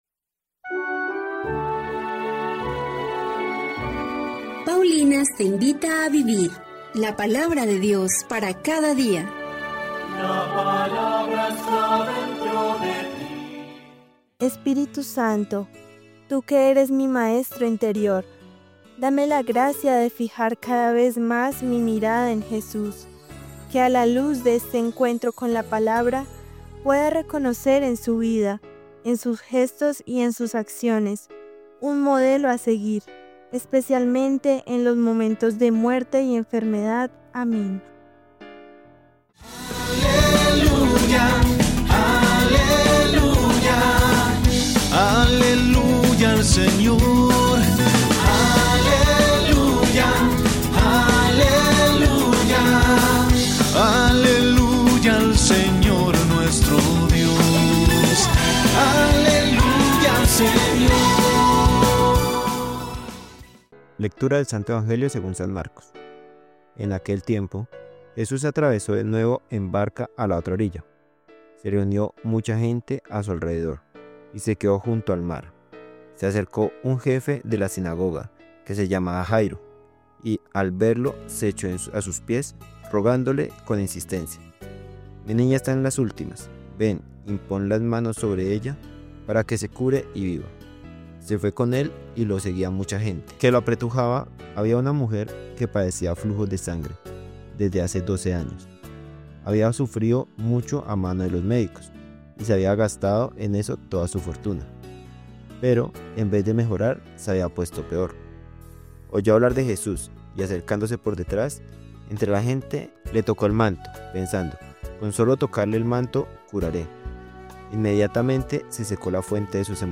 Lectura del libro de Job 7, 1-4. 6-7